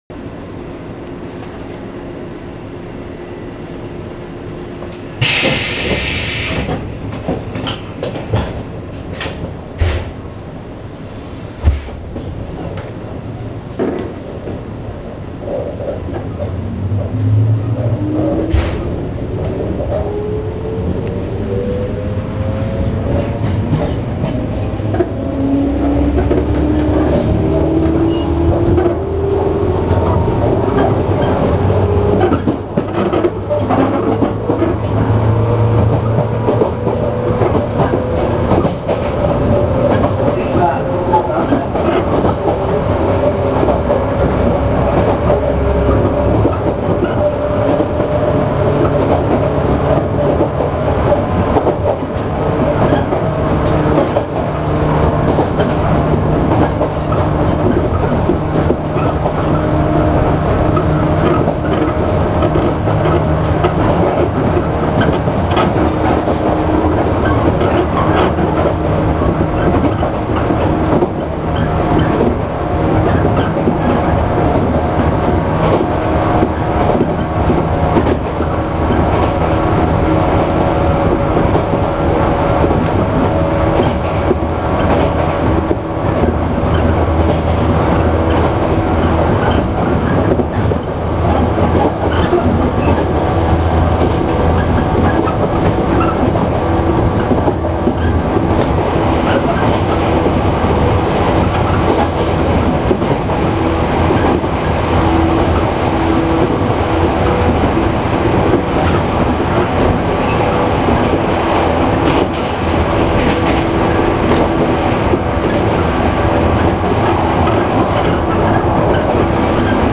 ・115系走行音
【上越線】上牧→後閑（6分4秒：1.93MB）…T1043編成にて
ごく普通の抵抗制御。113系や415系でもよく聞ける標準的な音です。